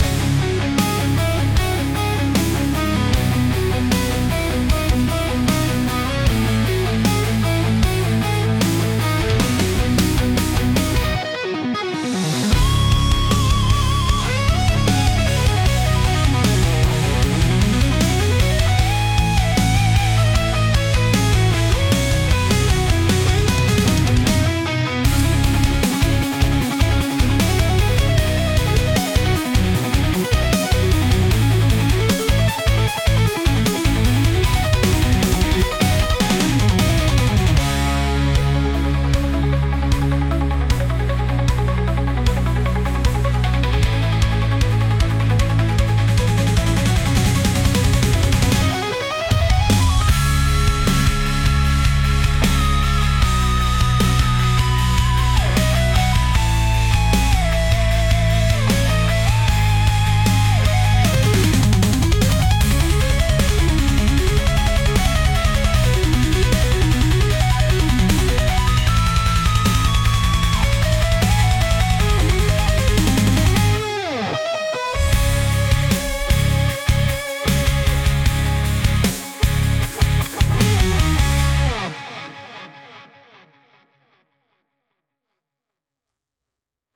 Genre: Rock Mood: Electric Editor's Choice